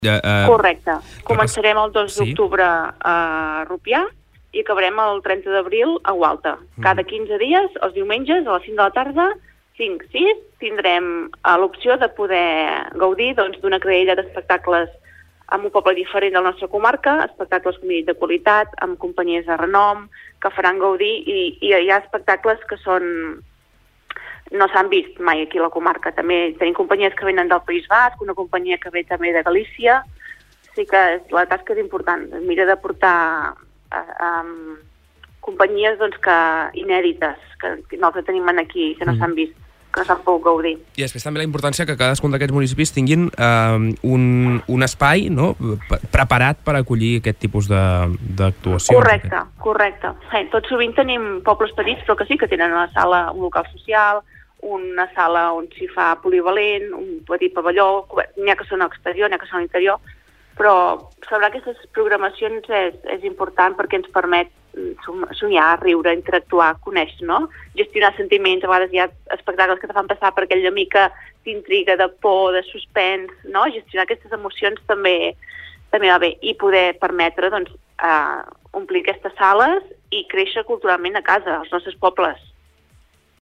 Avui hem parlat amb Agnès Gasull, consellera comarcal de Cultura sobre el festival Empordanet Escena, un circuit de teatre familiar amb espectacles a 14 municipis del Baix Empordà.